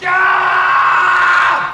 One of the most iconic Arnold Schwarzenegger quotes.
"(kindergardenCop scream)"
kindergardenCop_scream.mp3